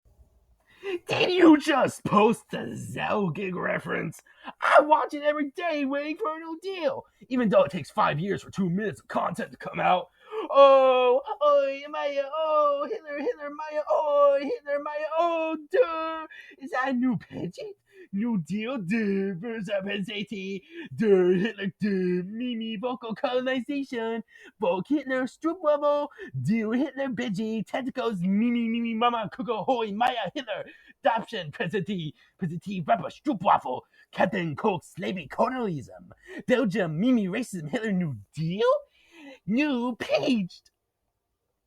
Zellig_chant.mp3